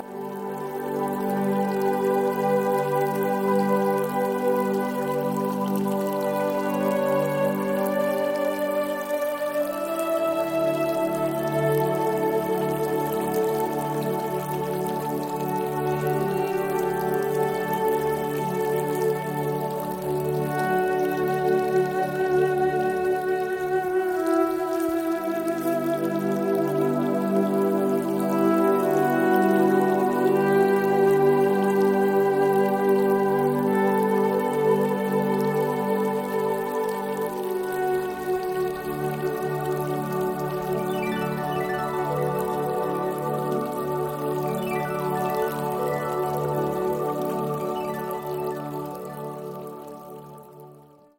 A heavenly angelic healing experience.